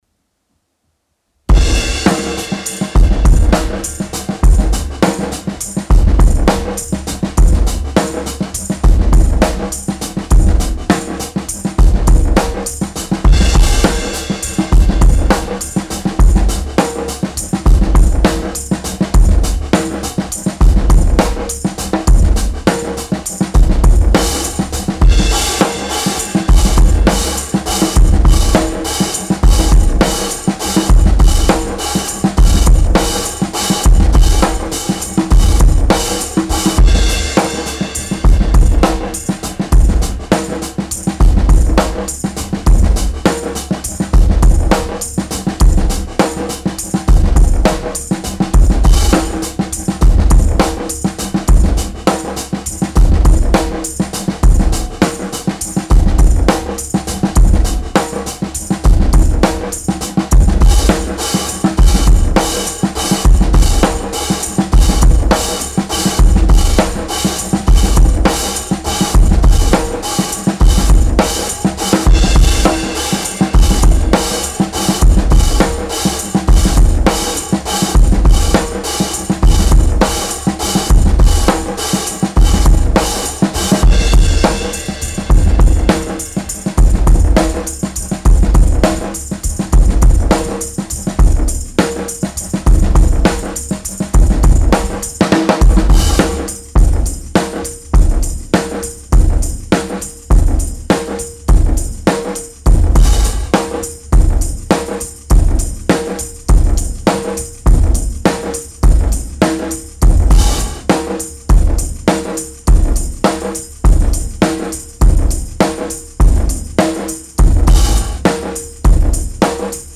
Genre:Breakbeat, Deathpop
Tempo:204 BPM (5/4)
Kit:Marching kit 30"
Mics:12 channels